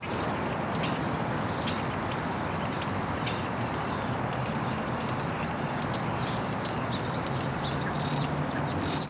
Zvok ptic na Zemlji [.wav, 10sek]
Sounds-of-Mars_birds_Earth.wav